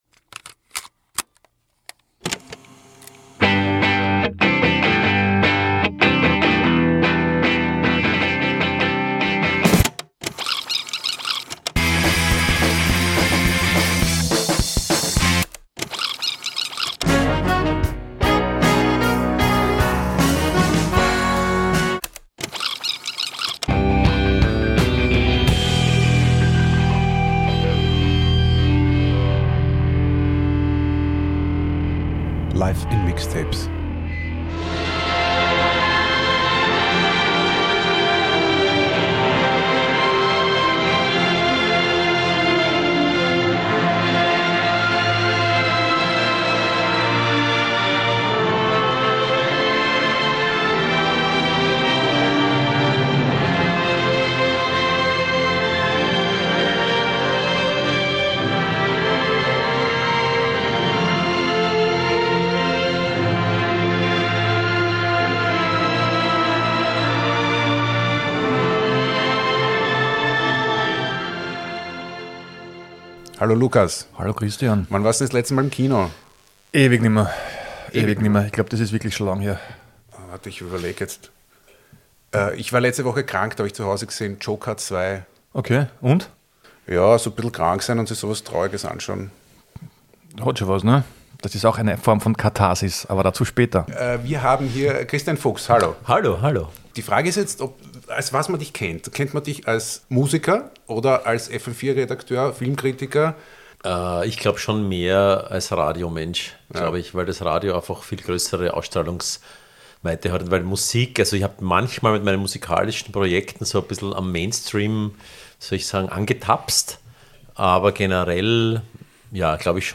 Wir sprechen mit ihm über sein Buch, schwärmen über Filme und hören unsere liebsten Soundtracks und Film-Scores. Schwerpunkt von Teil 1 unseres Gesprächs: die Gemeinsamkeiten und Unterschiede von Film und Musik, das transzendente Kino Terrence Malicks, Filme als Katharsis und die Möglichkeit der Trennung von Kunst und Künstler.